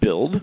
build.mp3